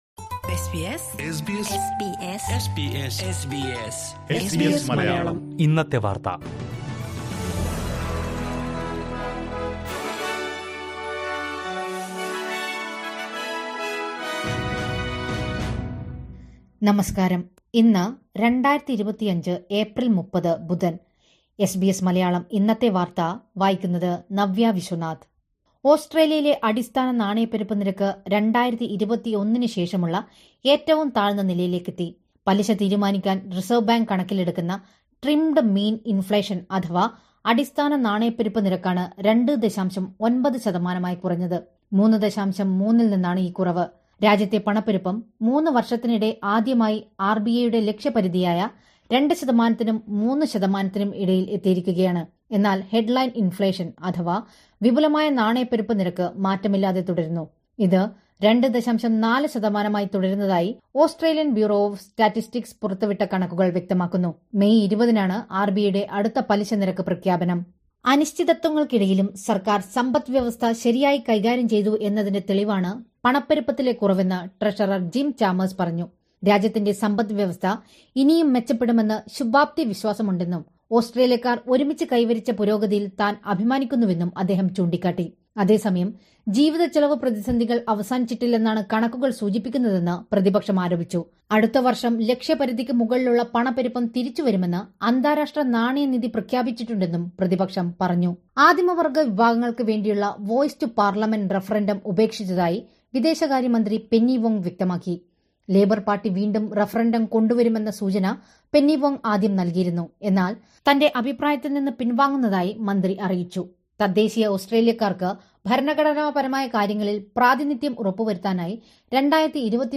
2025 ഏപ്രില്‍ 30ലെ ഓസ്‌ട്രേലിയയിലെ ഏറ്റവും പ്രധാന വാര്‍ത്തകള്‍ കേള്‍ക്കാം...